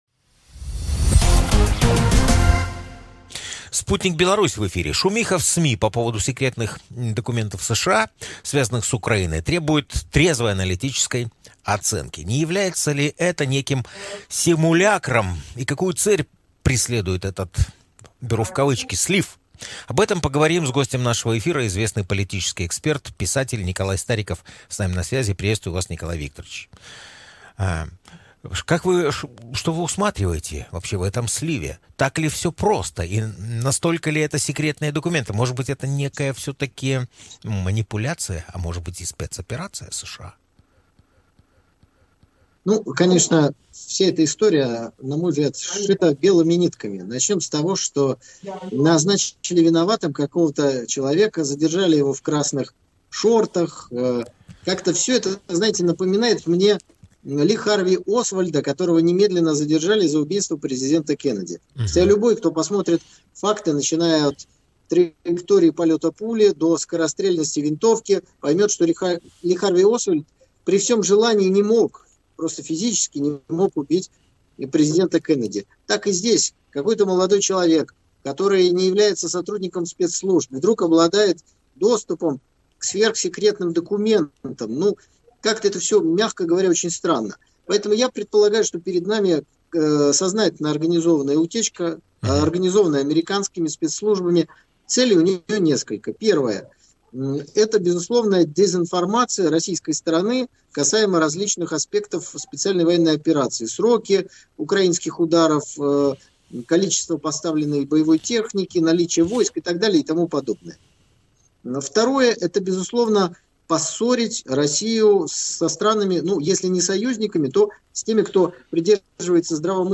В интервью радио Sputnik рассказал о том, что утечка секретных документов Пентагона, связанных с боевыми действиями на Украине, организована самими американскими спецслужбами и преследует сразу несколько целей.